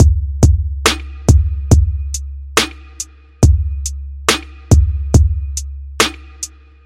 钟声钢琴
描述：在FL Studio中使用omnisphere的钟声钢琴
标签： 140 bpm Rap Loops Bells Loops 2.31 MB wav Key : Unknown FL Studio
声道立体声